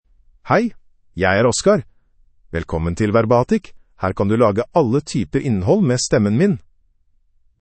Oscar — Male Norwegian Bokmål AI voice
Oscar is a male AI voice for Norwegian Bokmål (Norway).
Voice sample
Listen to Oscar's male Norwegian Bokmål voice.
Male